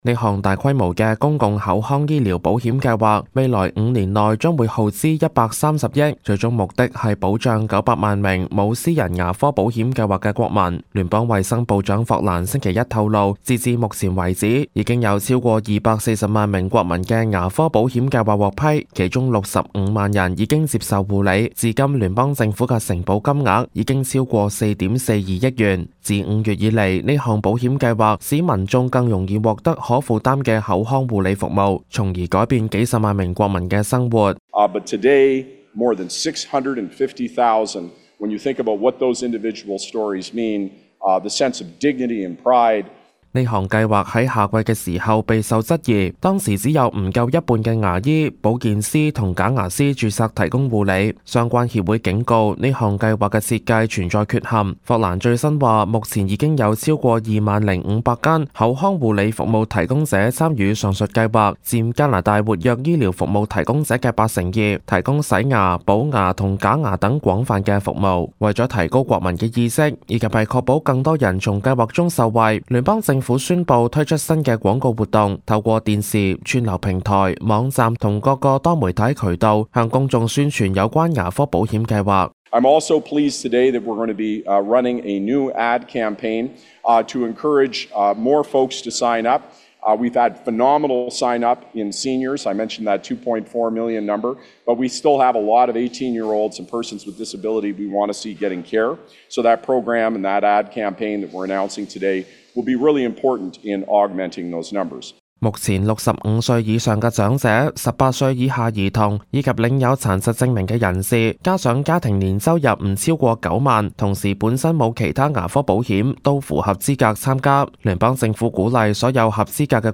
報道